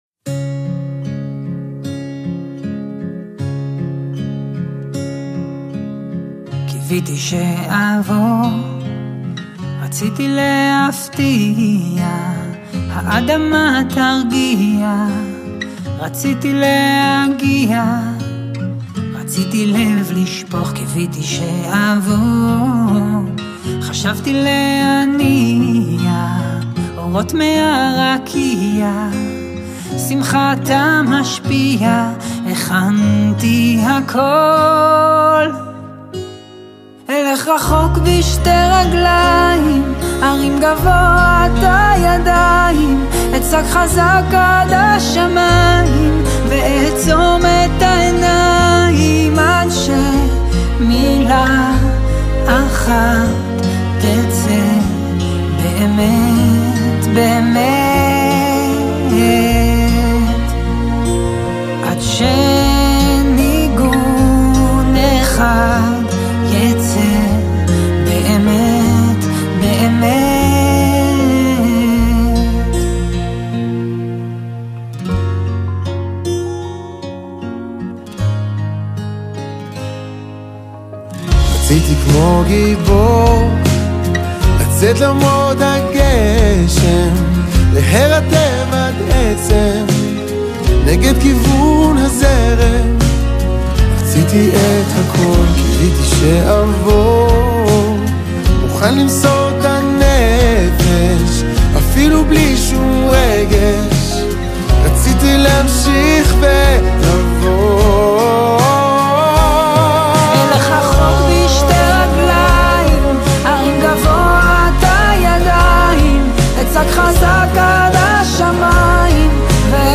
לדואט מרגש